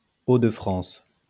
wymowa, o də fʁɑ̃s) – region administracyjny Francji, w północnej części kraju.